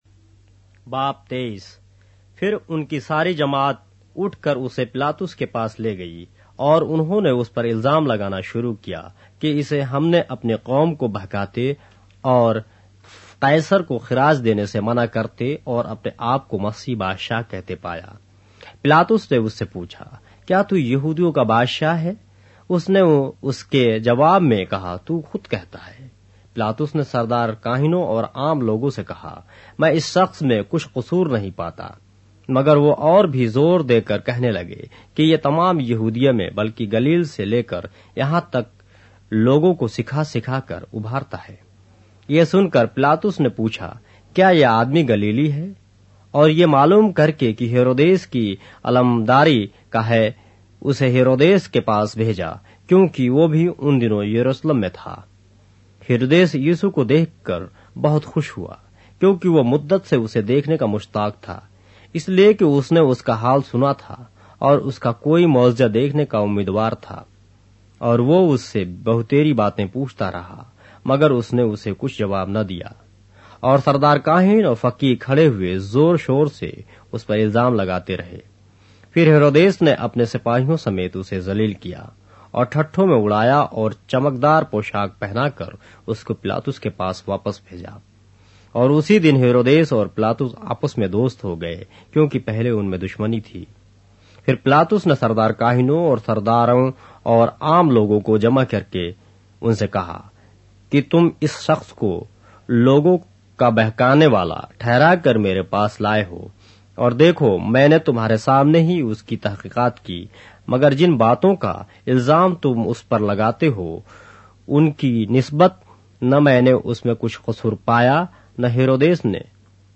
اردو بائبل کے باب - آڈیو روایت کے ساتھ - Luke, chapter 23 of the Holy Bible in Urdu